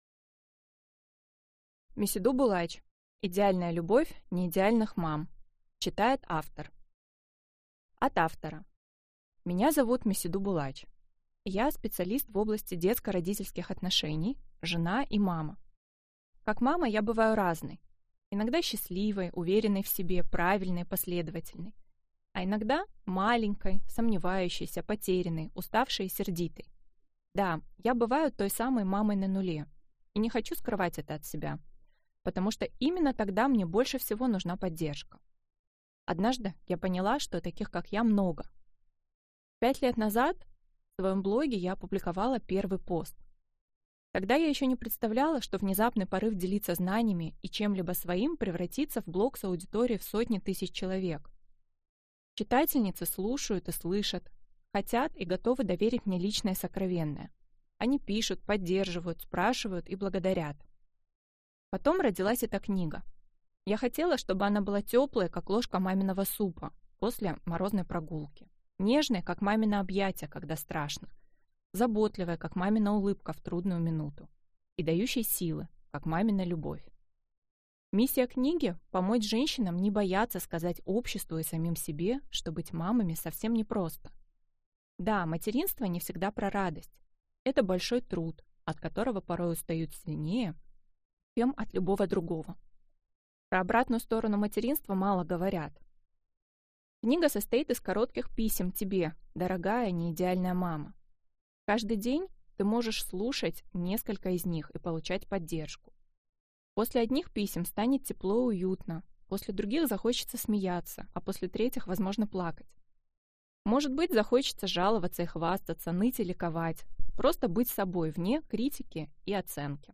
Аудиокнига Идеальная любовь неидеальных мам | Библиотека аудиокниг